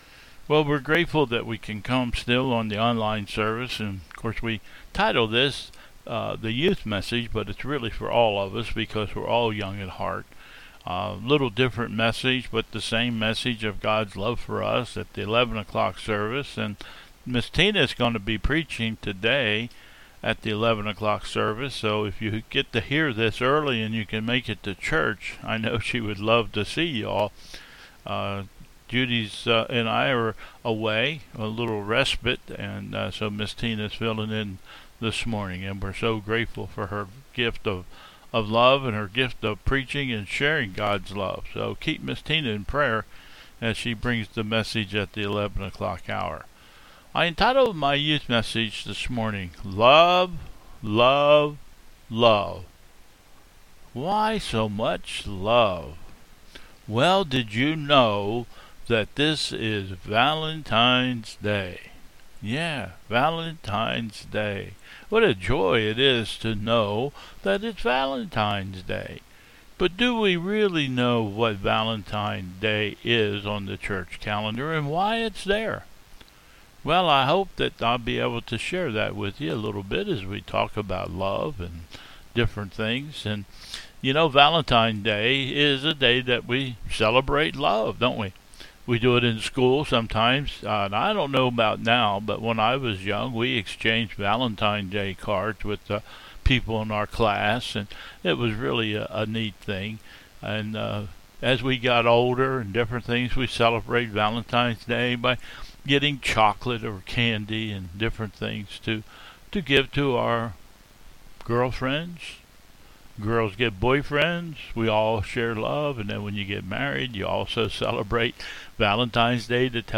Bethel 02/14/21 Service
- "Youth" Message : " Love, Love, Love " . (11:55) Though addressed to the "Youth", whom we truly miss seeing on Sundays, this message is for all of us.